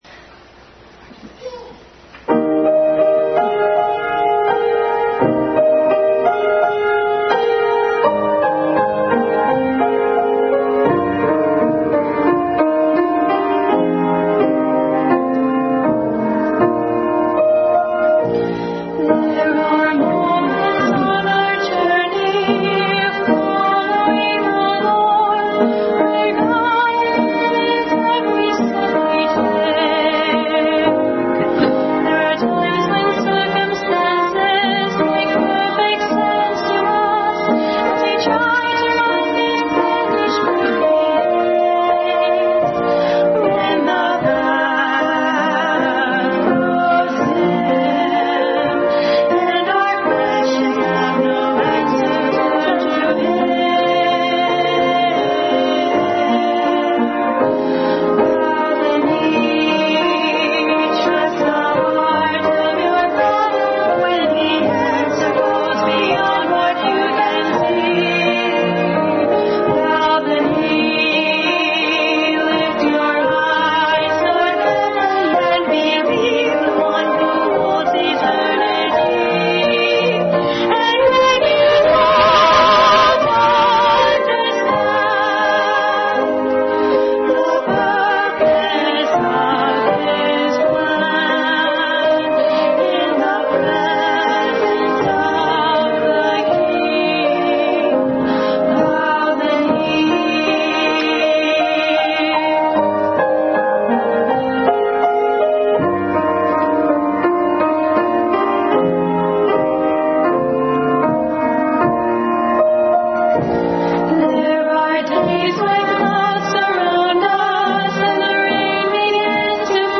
Family Bible Hour message, preceded by special music.